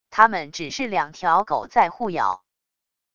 他们只是两条狗在互咬wav音频生成系统WAV Audio Player